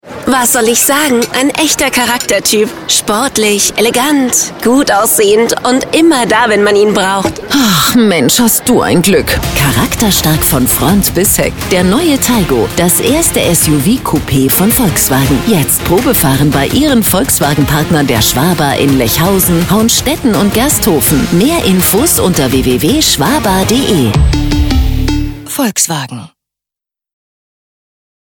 Funk-Spot: Volkswagen Taigo